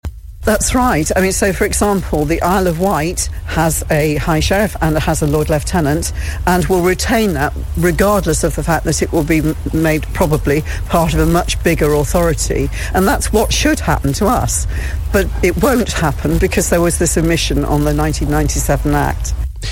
The Lord Lieutenant of Rutland, Dr Sarah Furness, says they need physical signatures, so the government will look at an amendment for what's believed to be a unique case prompted by local government reorganisation.